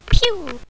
small_pew.ogg